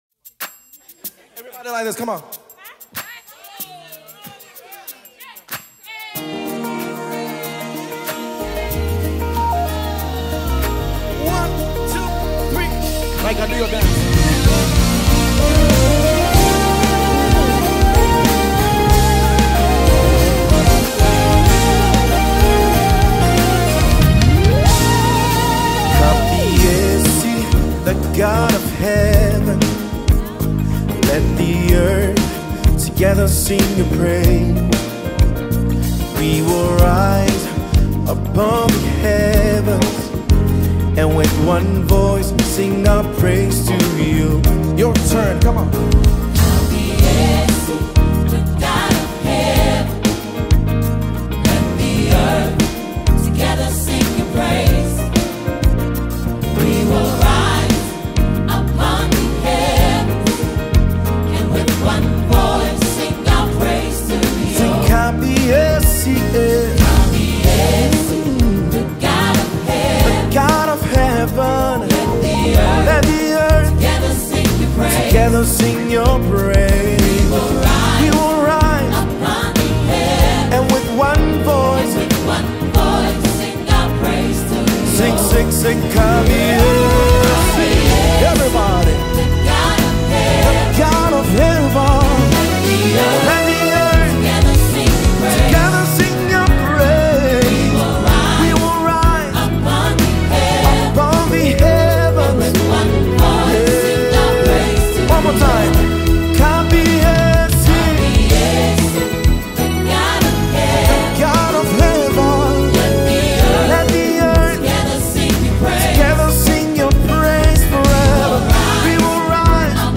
March 26, 2025 Publisher 01 Gospel 0